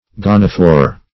Gonophore \Gon"o*phore\, n. [Gr.